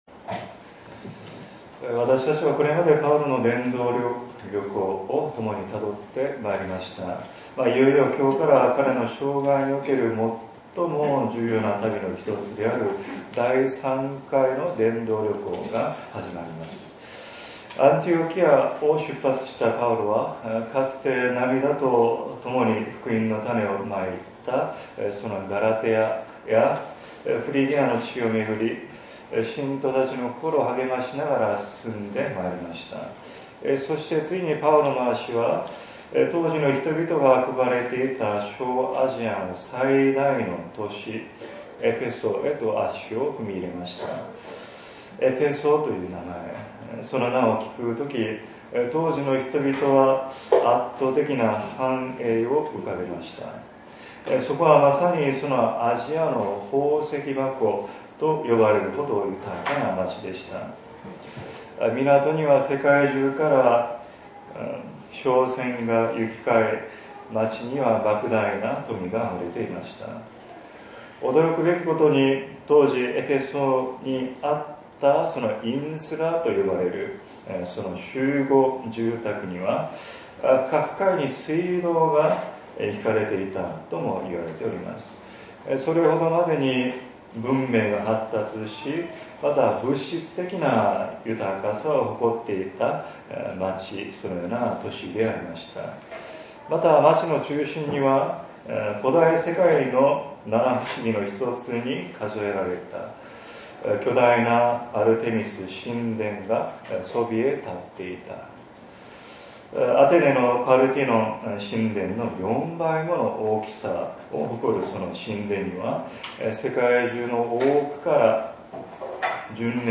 礼拝次第